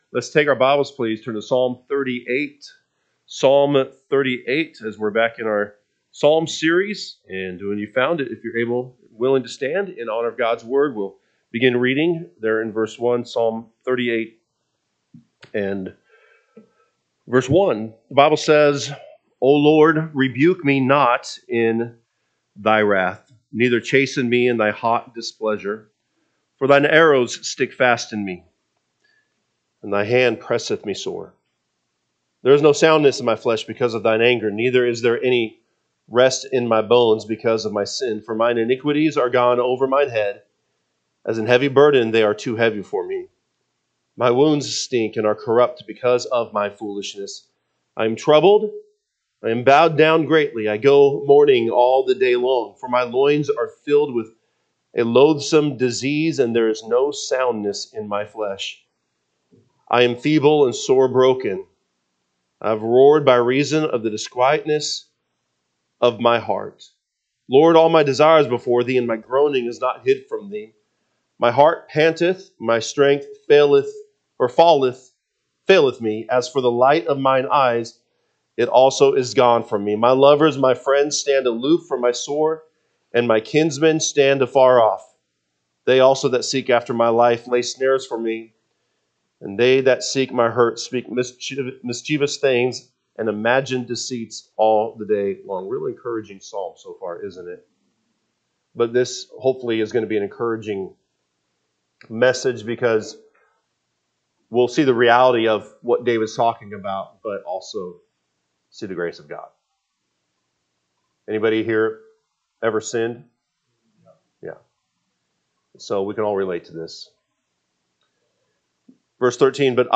Wednesday Evening